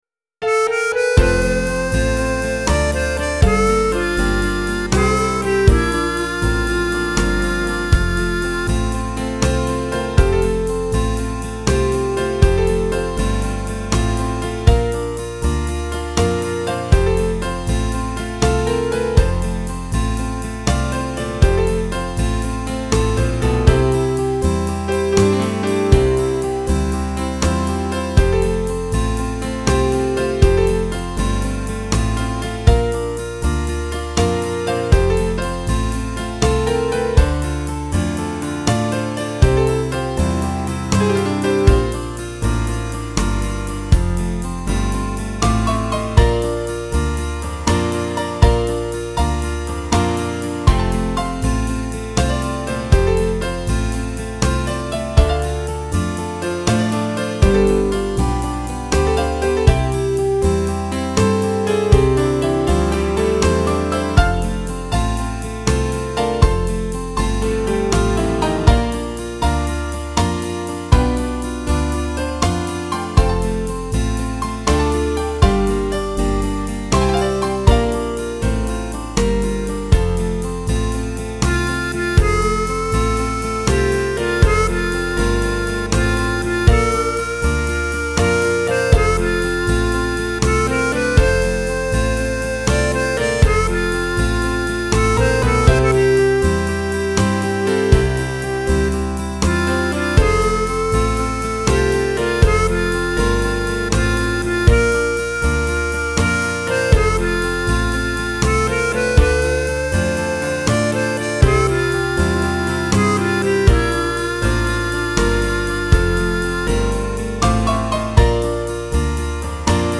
it became a classic country-pop favorite.